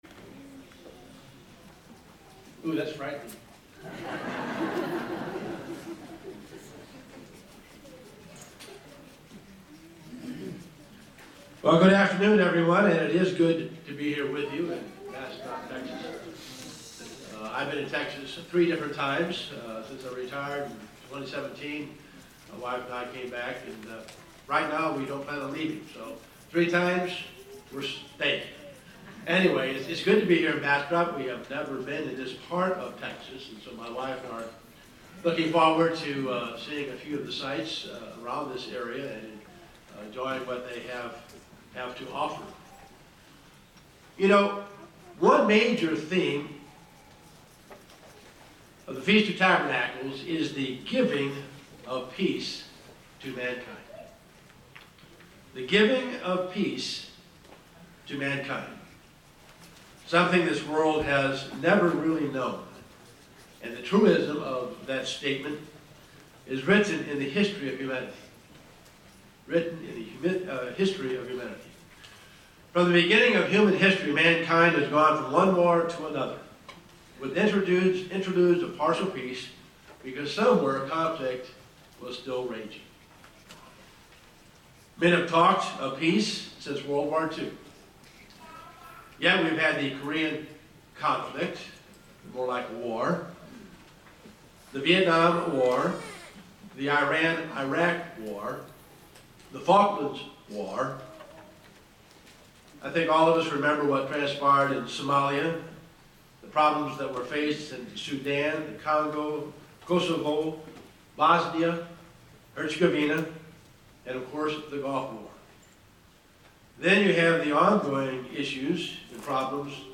This sermon was given at the Bastrop, Texas 2022 Feast site.